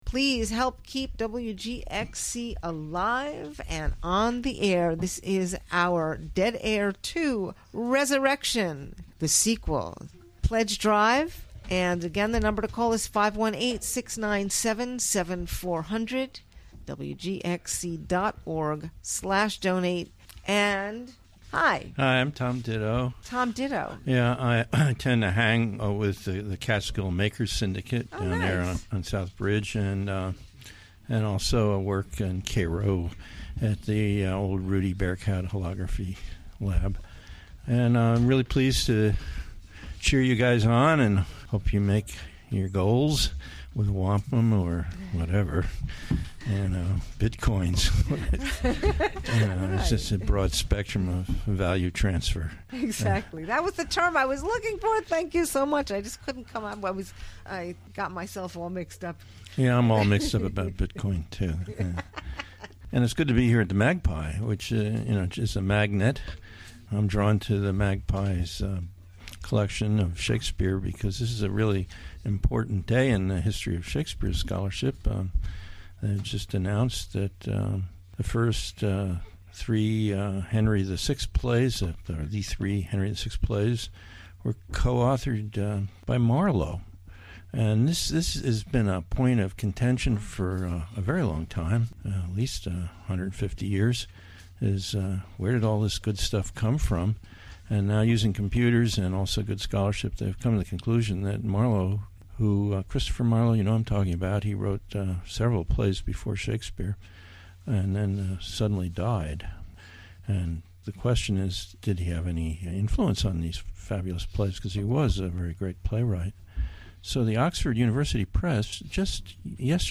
Special Remote Broadcast